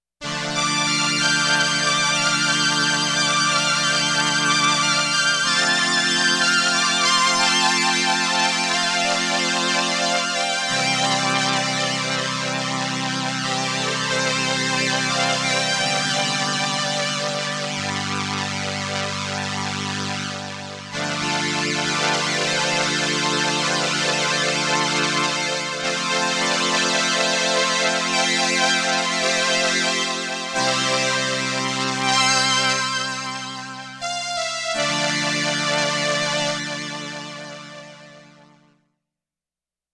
The Roland RS-202 is a classic 1970s "string ensemble" keyboard, with a sound and and an ensemble effect similar to the classic Solina.
30 STRINGS II with vibrato, sustain, and Ensemble I
30 STRINGS II with vibrato, sustain, and Ensemble I.mp3